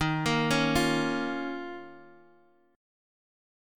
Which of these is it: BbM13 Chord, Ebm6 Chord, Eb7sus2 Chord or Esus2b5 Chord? Eb7sus2 Chord